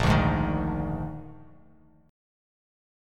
A#11 chord